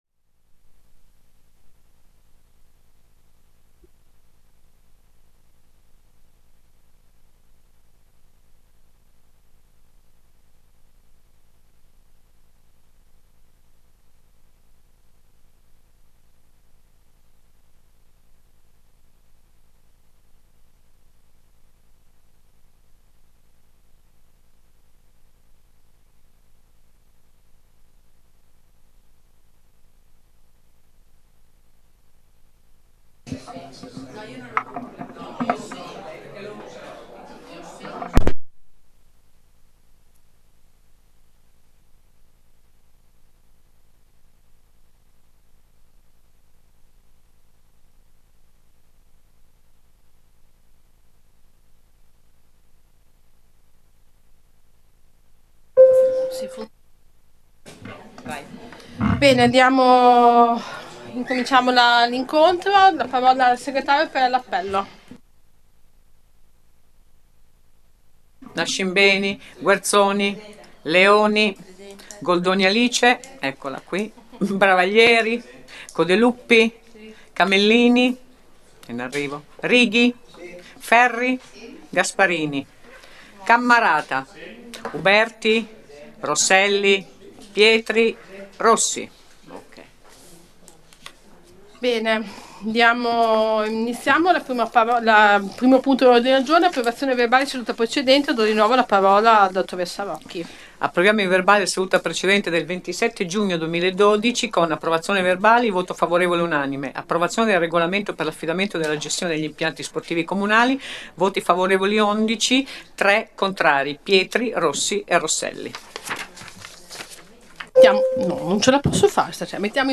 Accedendo a questa pagina è possibile ascoltare la registrazione della seduta del Consiglio comunale.